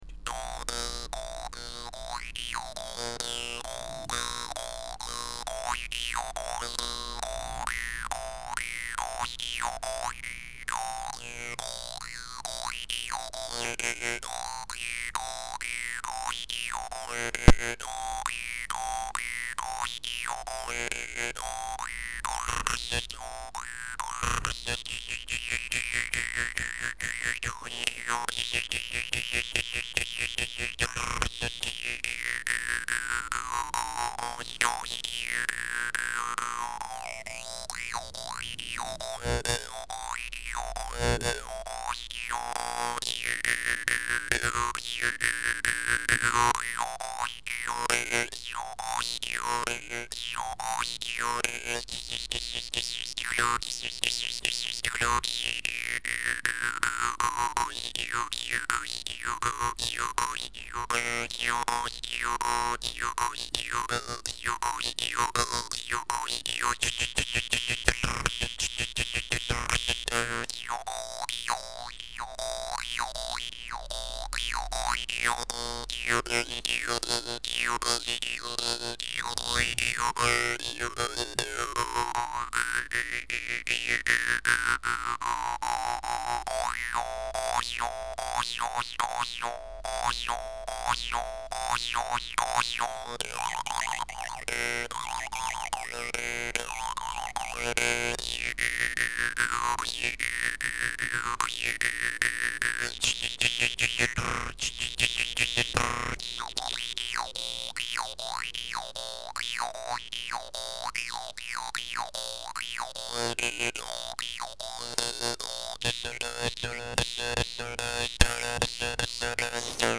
compos guimbardes laos
et voici l impro un peu olle olle...